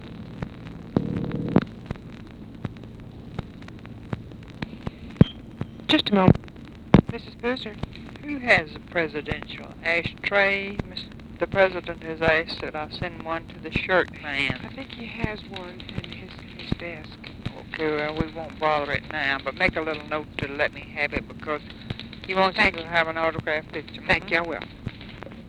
Conversation with UNIDENTIFIED FEMALE
Secret White House Tapes